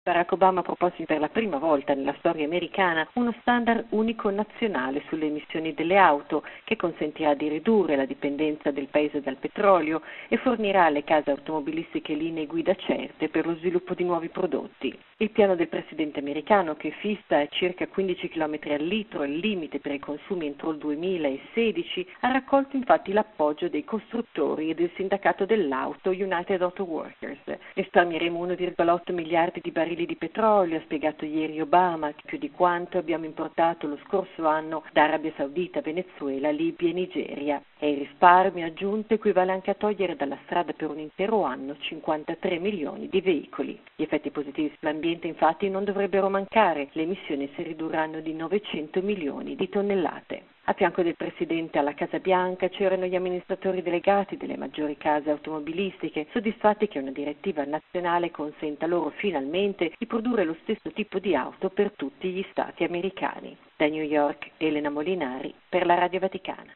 Il servizio da New York